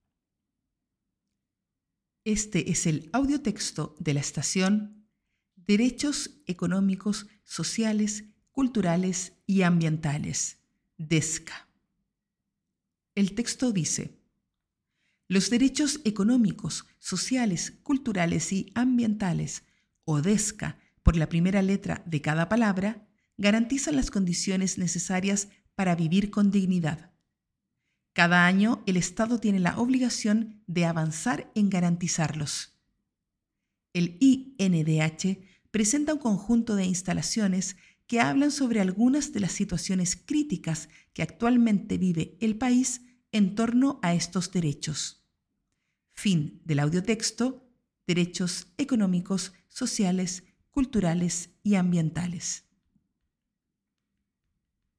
Audiotexto
DESCA-texto-de-sala.mp3